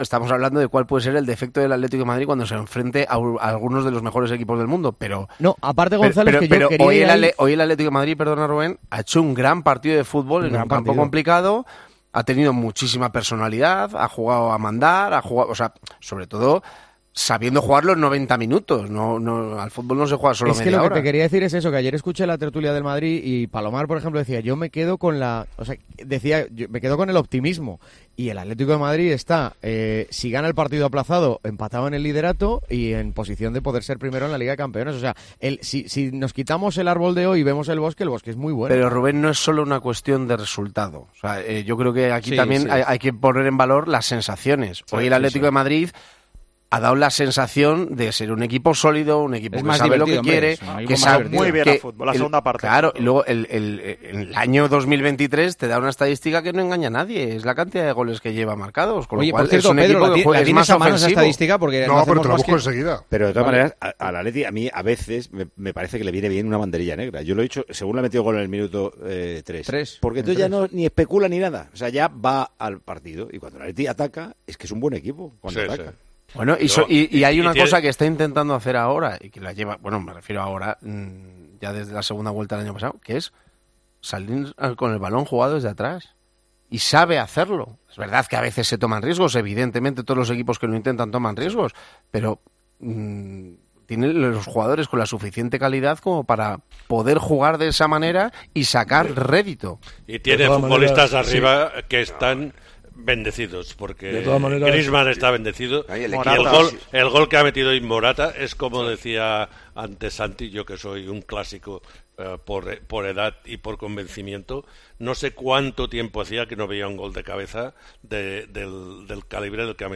AUDIO: Juanma Castaño y los tertulianos valoraron el empate del equipo rojiblanco contra el equipo escocés que le deja en la segunda posición del grupo.